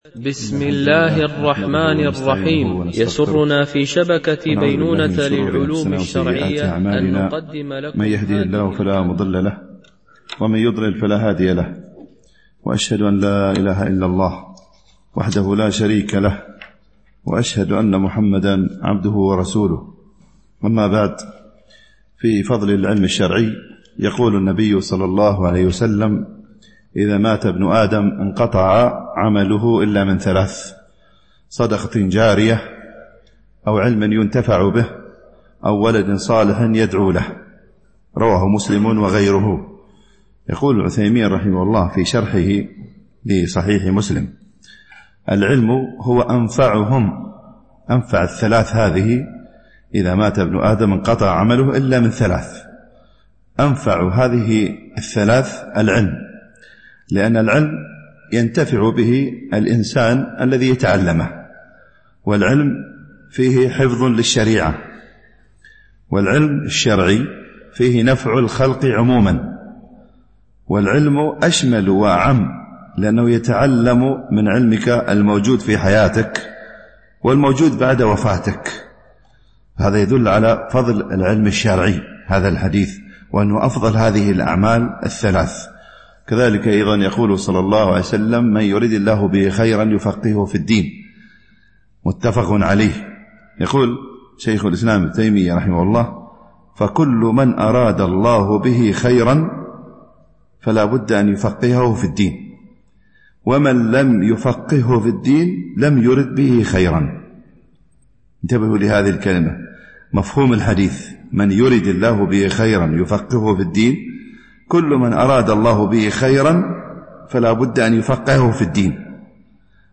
شرح الأربعين النووية ـ الدرس 50 (الحديث 32-33)